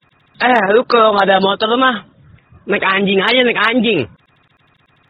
Kategori: Suara viral
Keterangan: Suara meme lucu 'Eh, lu! Kalau enggak punya motor, mending naik anjing aja, naik anjing!'